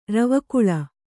♪ ravakuḷa